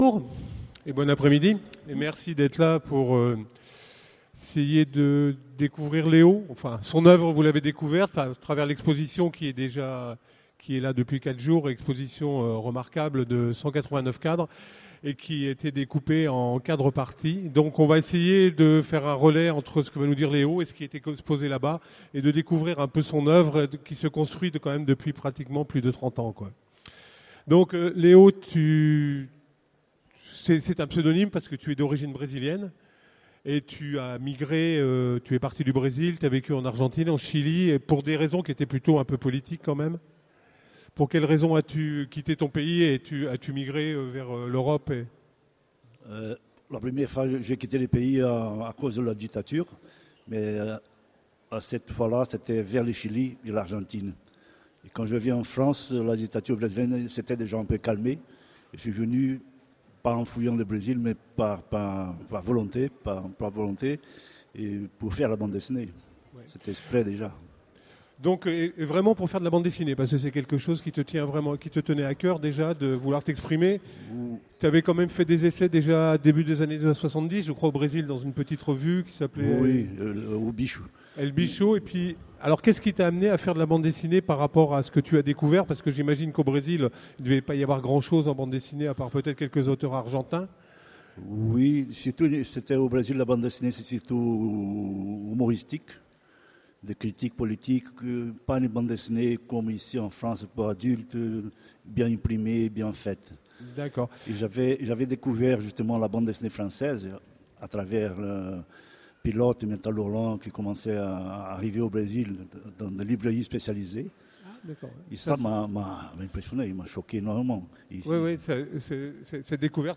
Voici l'enregistrement de la rencontre avec le dessinateur Leo aux Utopiales 2009.